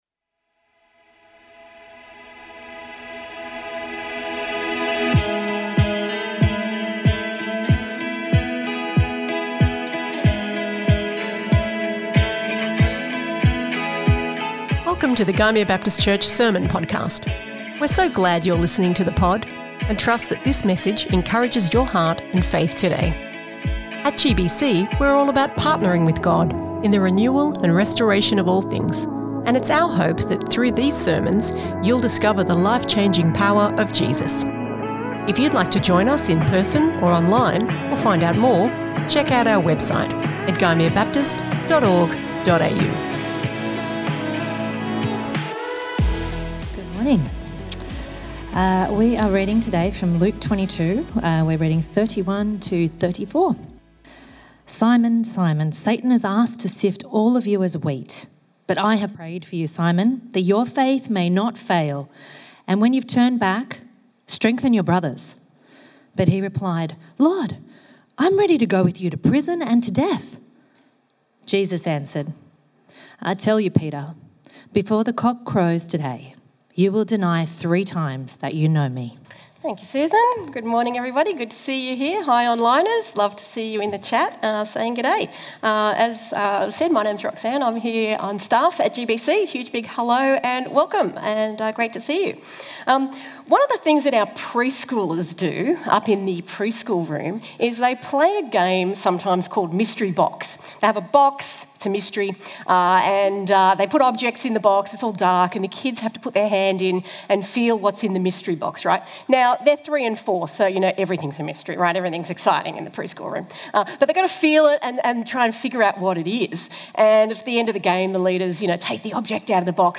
GBC | Sermons | Gymea Baptist Church
This message from our Sunday church service is part of the resources we provide as we seek to see lives changed by Jesus.